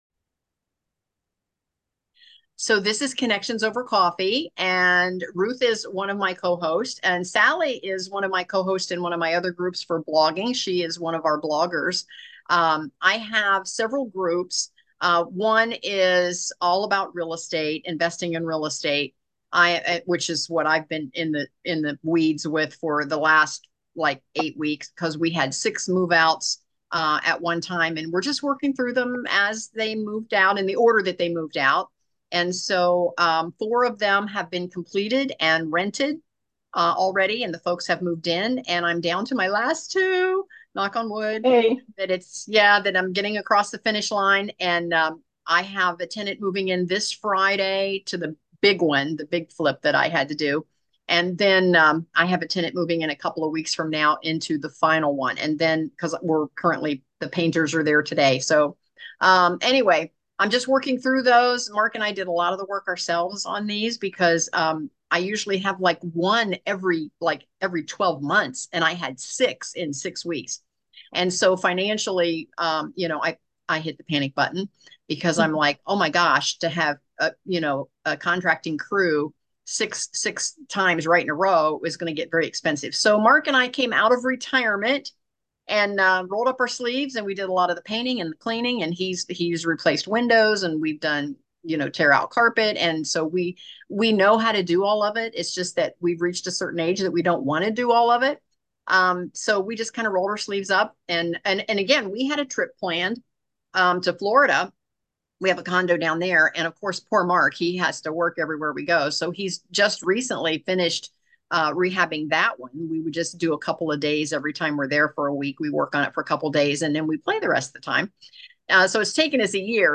Connections Over Coffee... where we gather each month to discuss various engaging and relevant Hot Topics.